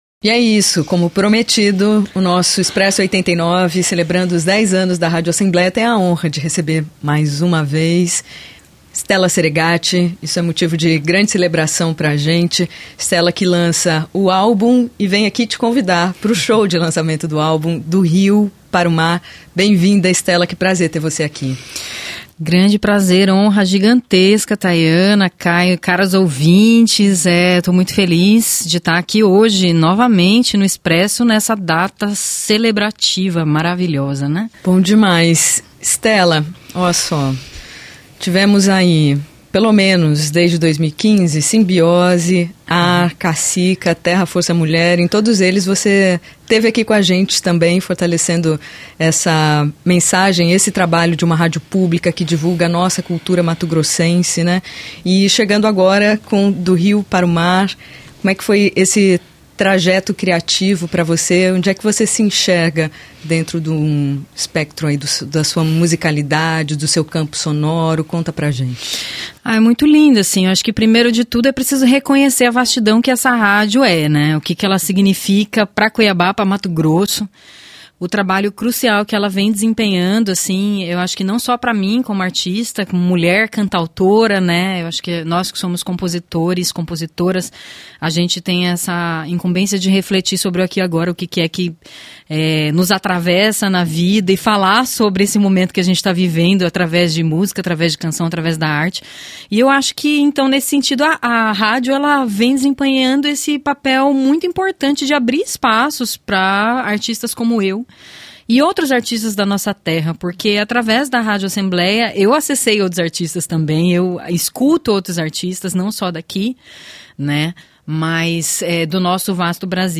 Expresso 89 - Entrevista